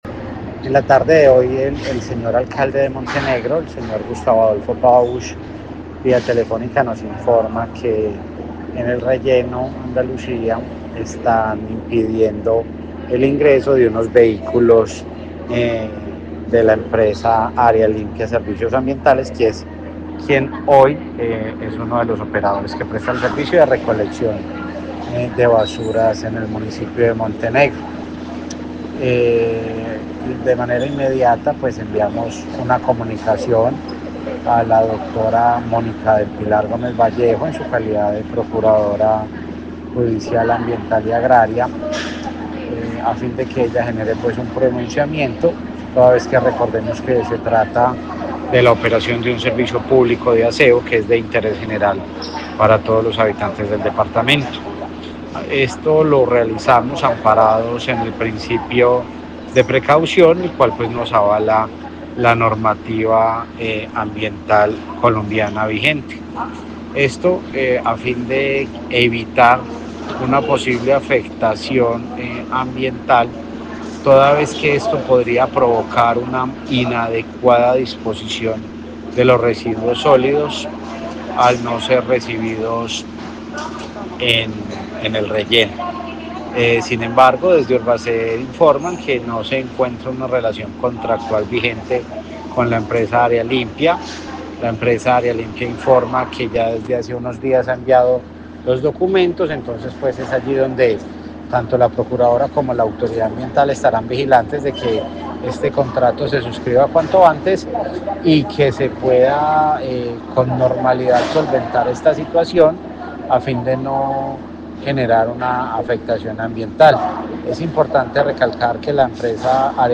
Juan Esteban Cortés, director (E) CRQ, Quindío